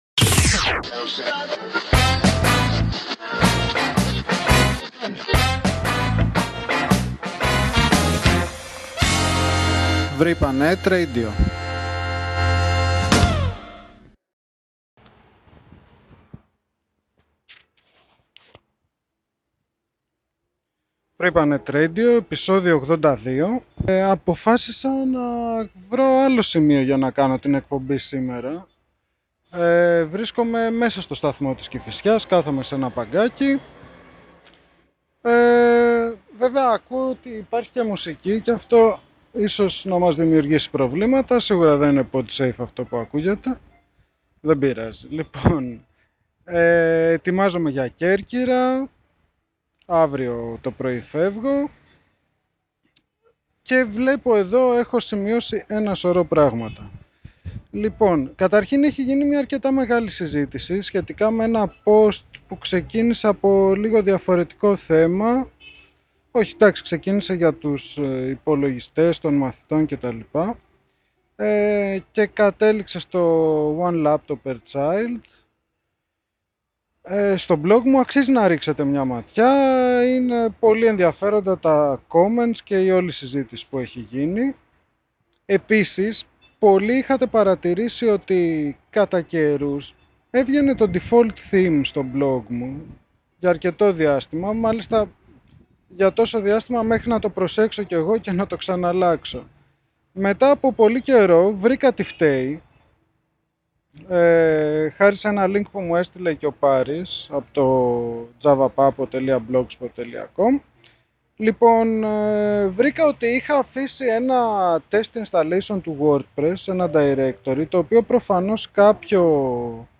Ο ήχος τα παίζει σε ένα-δύο σημεία, δεν ξέρω γιατί...